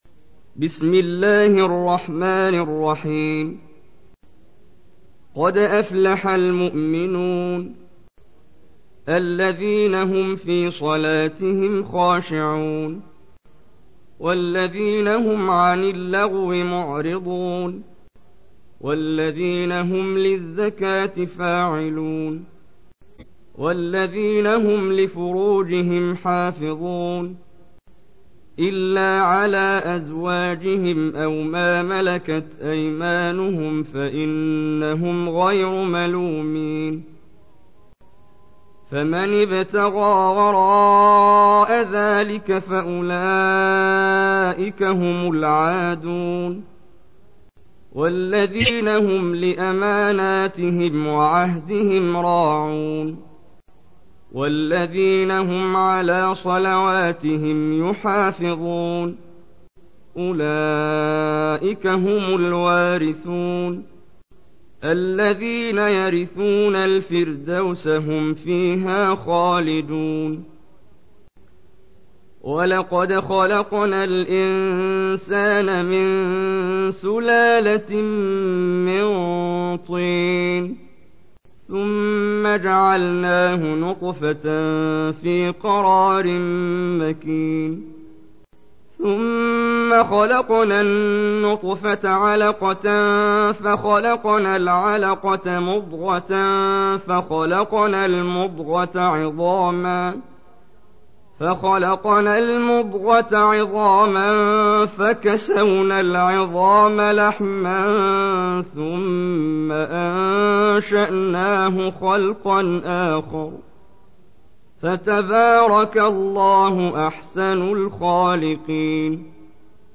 تحميل : 23. سورة المؤمنون / القارئ محمد جبريل / القرآن الكريم / موقع يا حسين